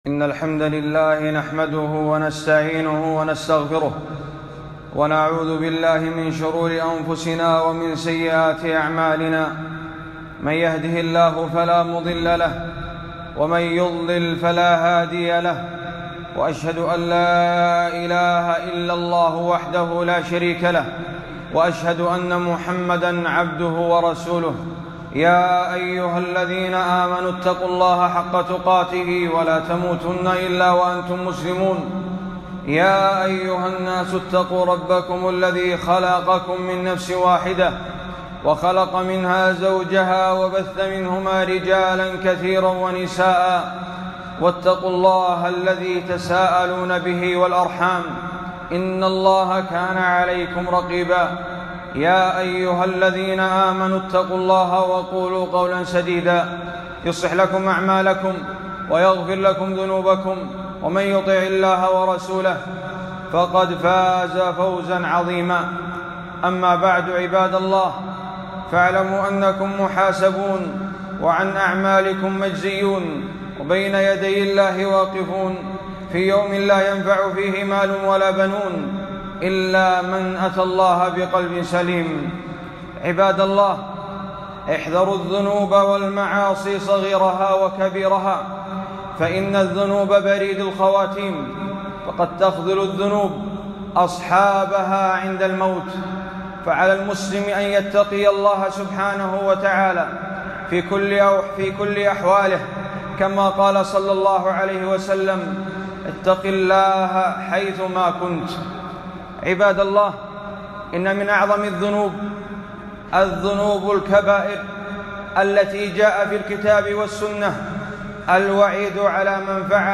خطبة - المتوعدون في الكتاب والسنة بعدم تكليم الله لهم - دروس الكويت